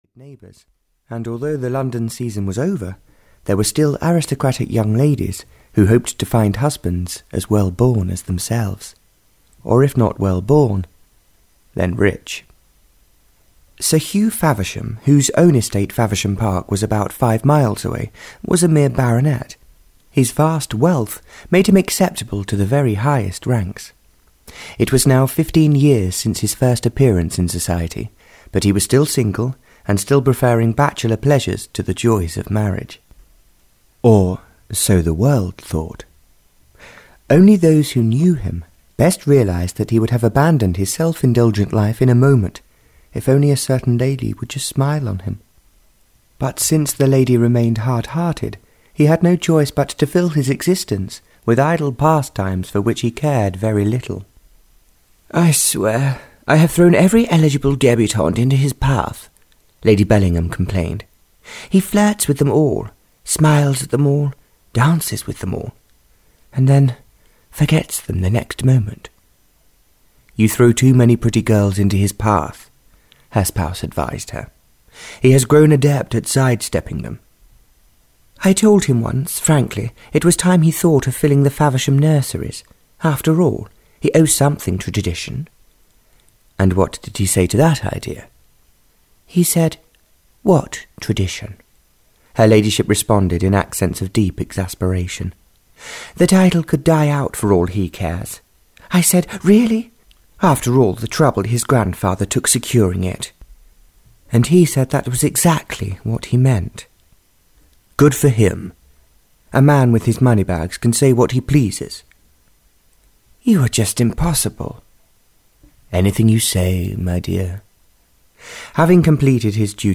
Audio knihaJourney to Happiness (Barbara Cartland’s Pink Collection 28) (EN)
Ukázka z knihy